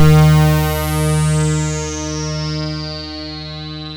KORG D#3 1.wav